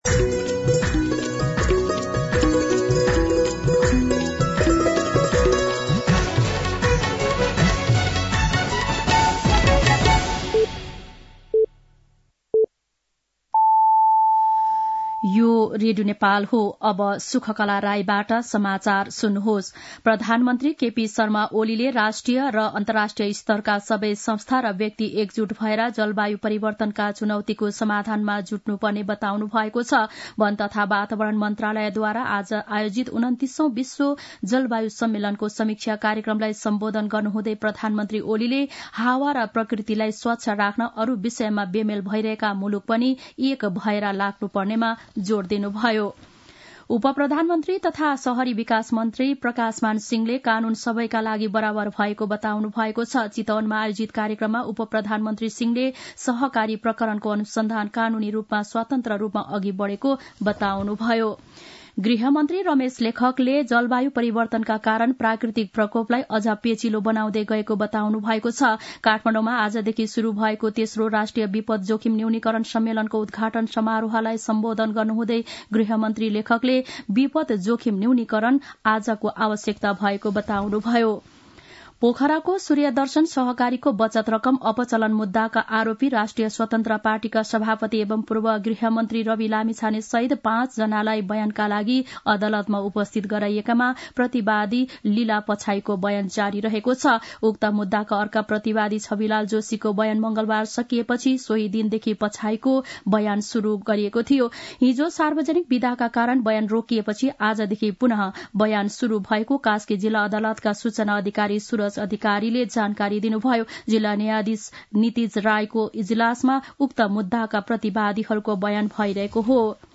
दिउँसो ४ बजेको नेपाली समाचार : १२ पुष , २०८१
4-pm-Nepali-News-3.mp3